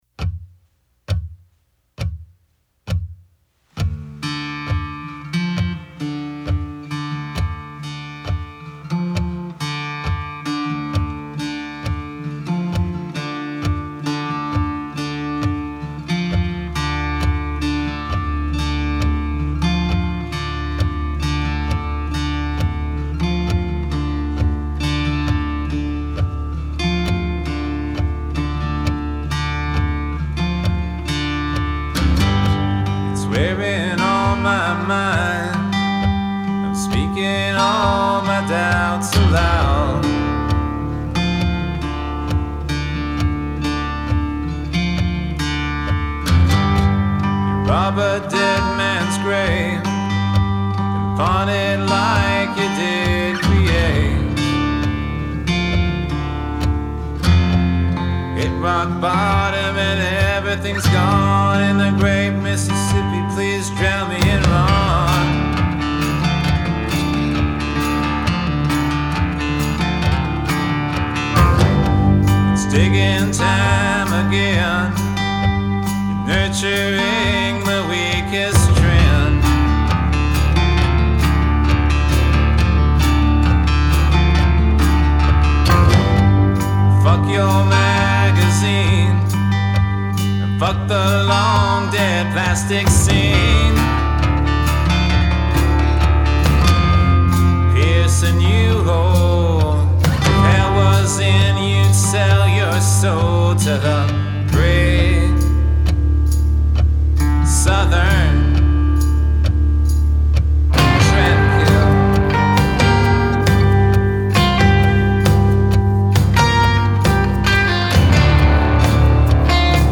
4 track EP of Heavy Metal covers.
Guitars & Bass
Drums